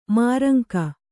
♪ māranka